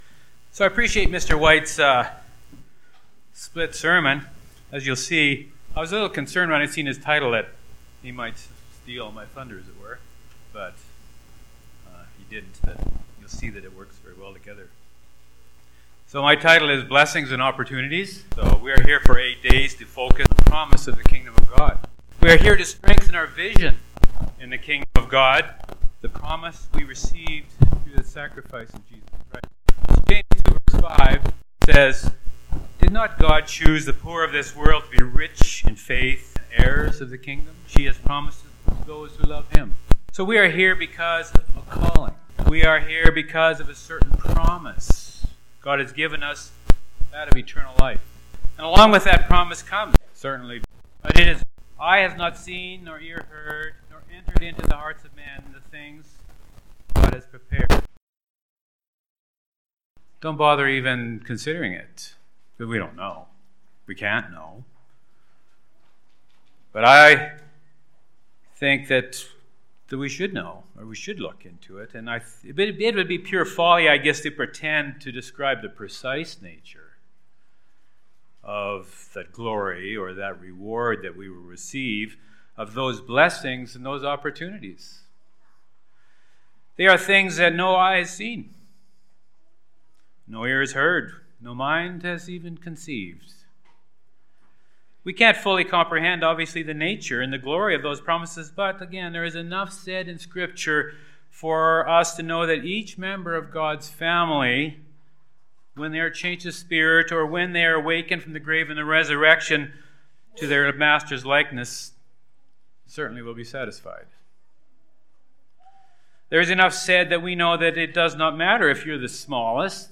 This sermon was given at the Canmore, Alberta 2016 Feast site.